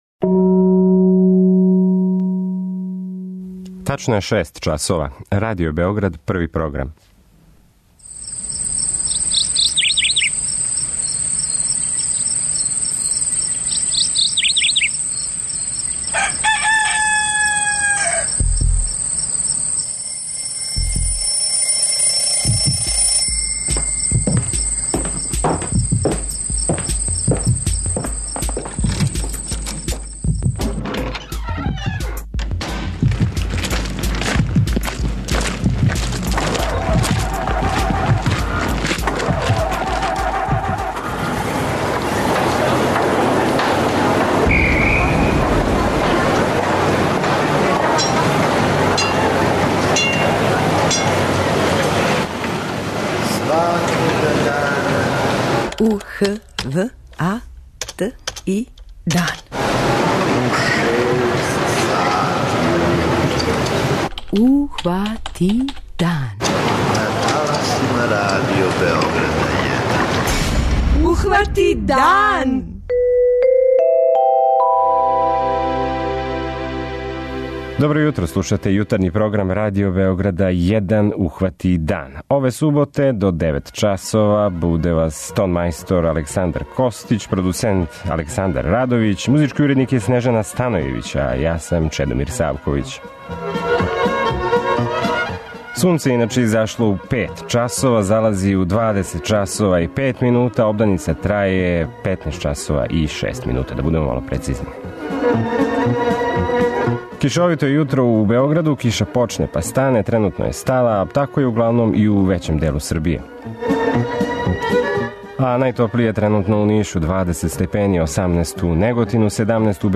преузми : 85.78 MB Ухвати дан Autor: Група аутора Јутарњи програм Радио Београда 1!